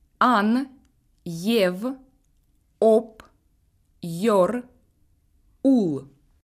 En fin de mot, la consonne seule est dure aussi: